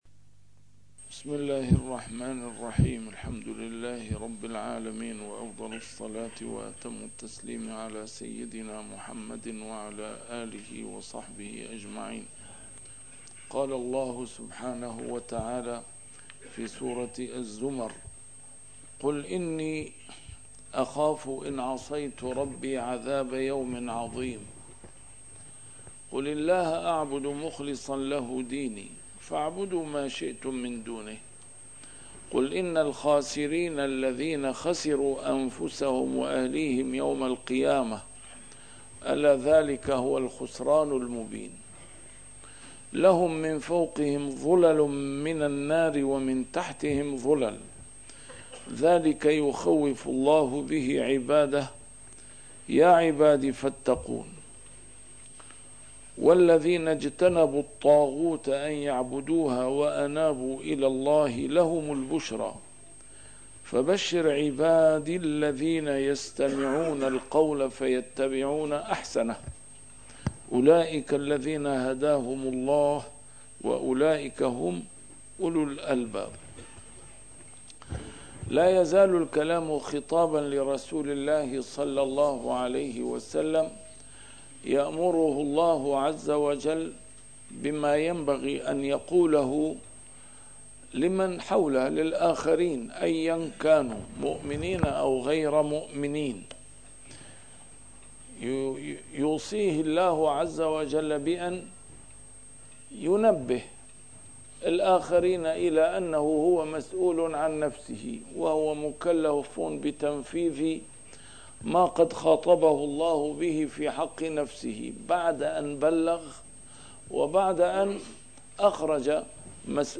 نسيم الشام › A MARTYR SCHOLAR: IMAM MUHAMMAD SAEED RAMADAN AL-BOUTI - الدروس العلمية - تفسير القرآن الكريم - تسجيل قديم - الدرس 487: الزمر 12-15